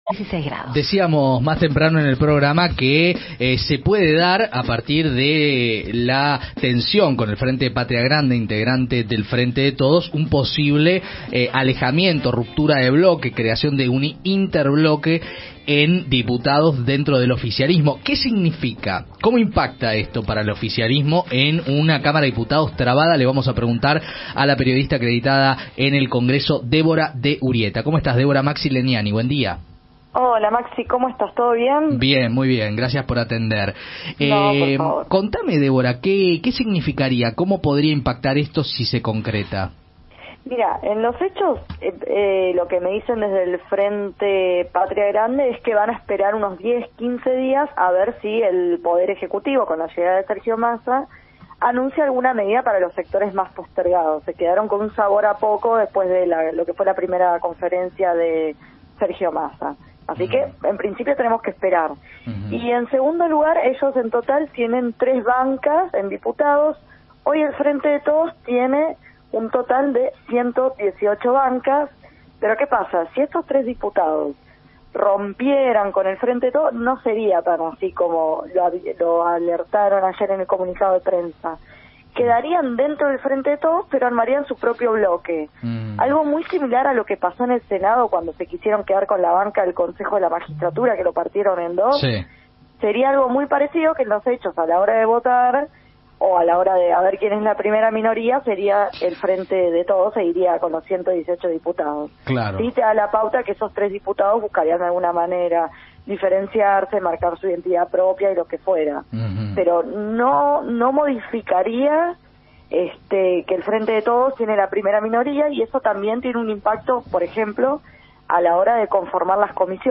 En diálogo con el equipo de RPM de La990, explicó que “los diputados de Patria Grande, van a esperar 10 o 15 días para ver si desde el Ejecutivo se anuncian medidas para los trabajadores. Si estos tres diputados rompieran con el FdT, armarían su propio bloque. Romperían pero no tanto”.